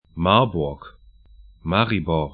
Pronunciation
Marburg 'ma:ɐbʊrk Maribor 'ma:ribɔr sl Stadt / town 46°33'N, 15°39'E